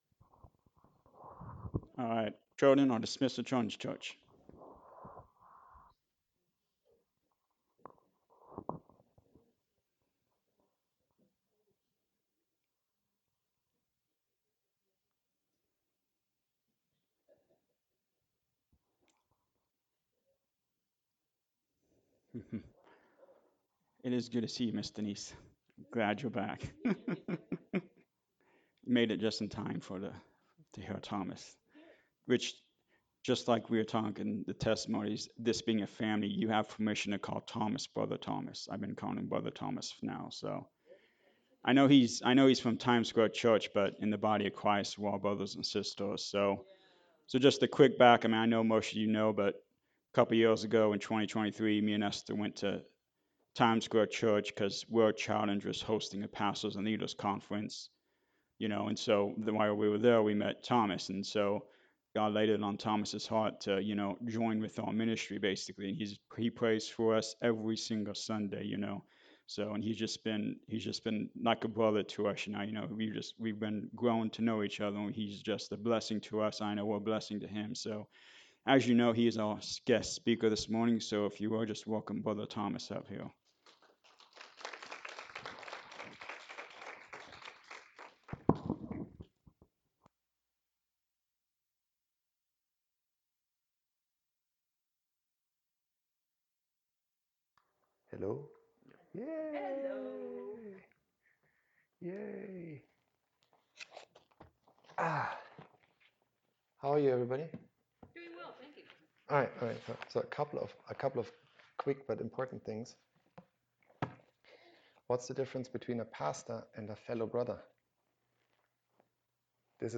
John 14:6 Service Type: Sunday Morning Service What Jesus do you really know?